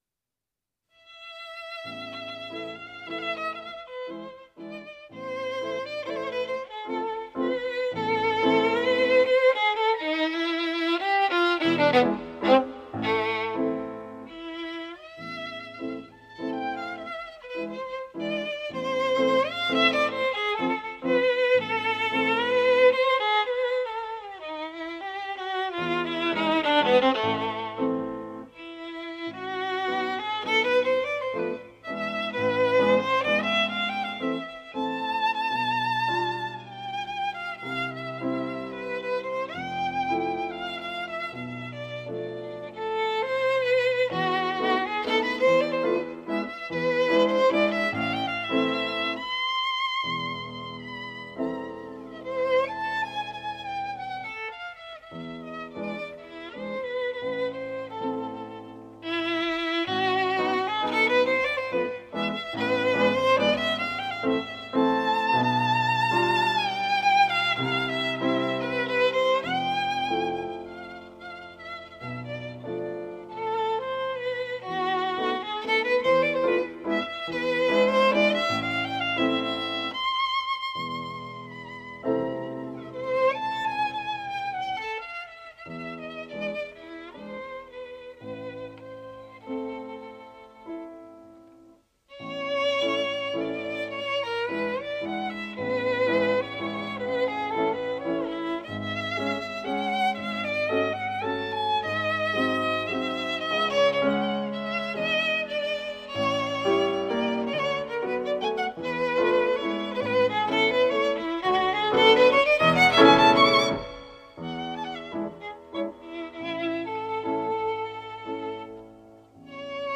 audio file بشنوید شوپن مازورکا را با تکنوازی ویولونژوزف گینگولد